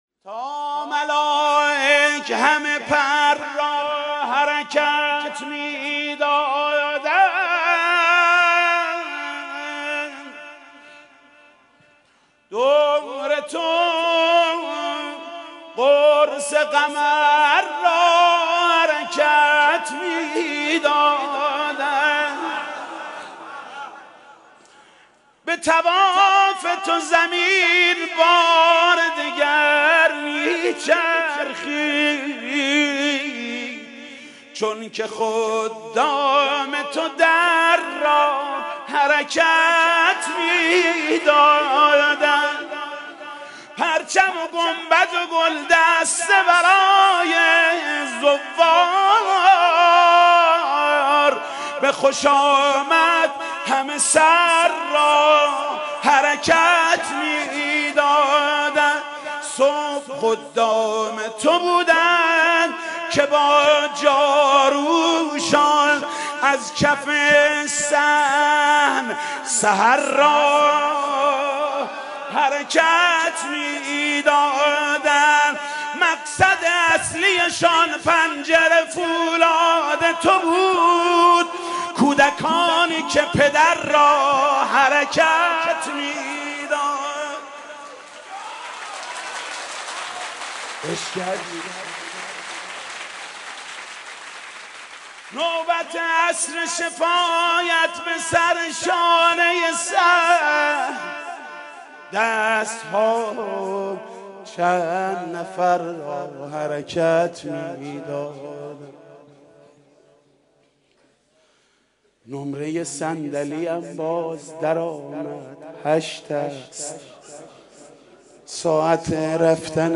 «میلاد امام رضا 1390» مدح: تا ملائک همه پر را حرکت می دادند